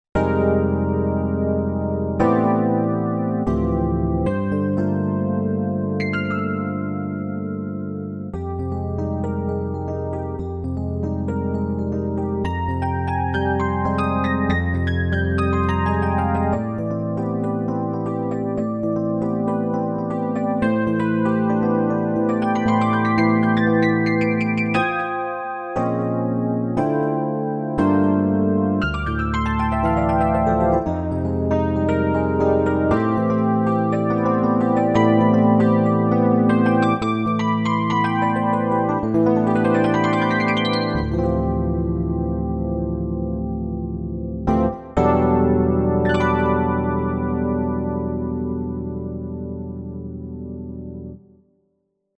Для лучшего представления о возможностях RD-800 и качестве звучания тембров SuperNATURAL мы предлагаем послушать демо-композиции, записанные нами специально для данной статьи:
Эффект тремоло.